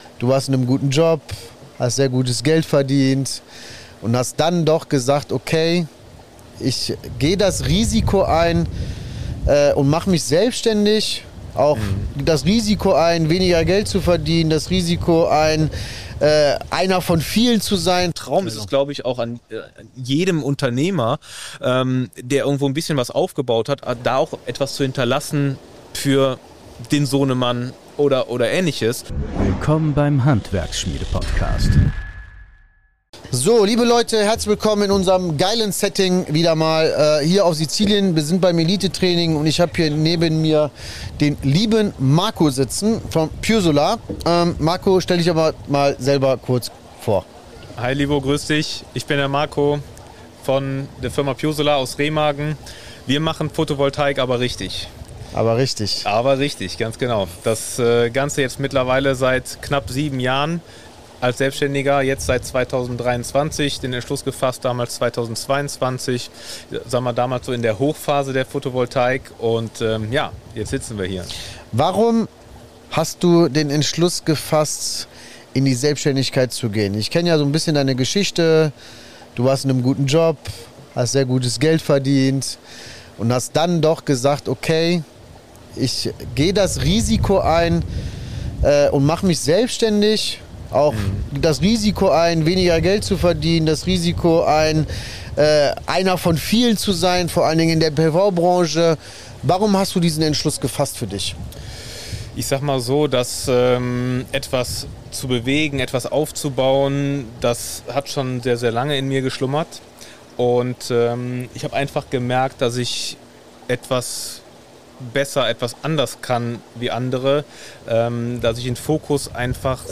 Ein Gespräch über Werte, Verantwortung und echtes Unternehmertum im Handwerk – weit weg von leeren Versprechen und Marketing-Blabla.